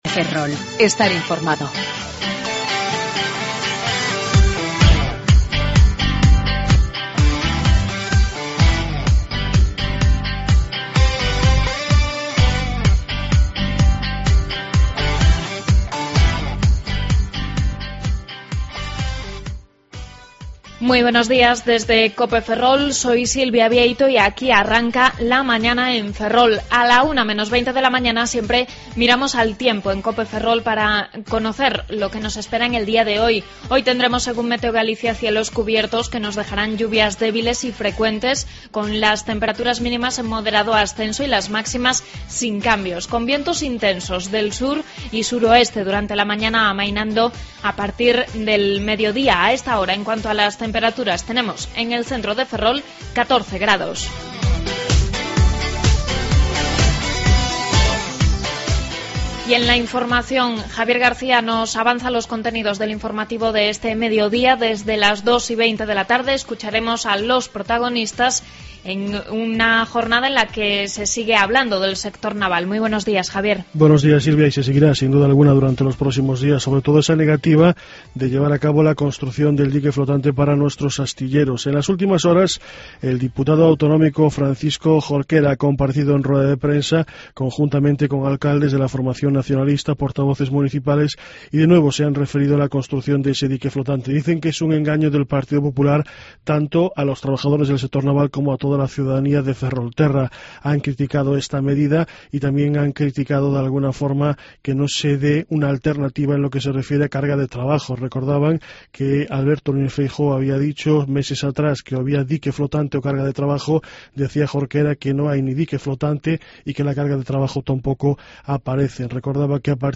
AUDIO: Avances informativos y contenidos de Ferrol, Eume y Ortegal.